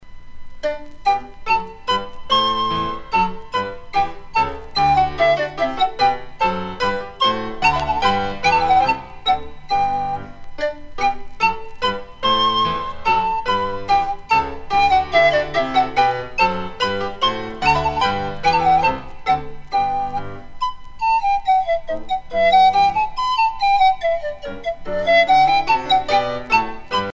danse : menuet
Pièce musicale éditée